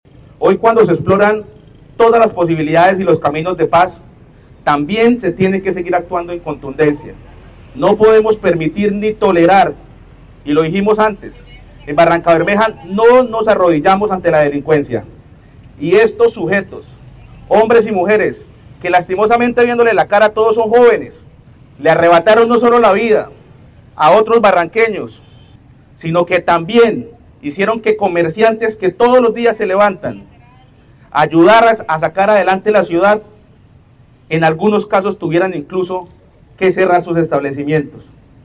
Eduardo Ramírez, secretario de Seguridad de Barrancabermeja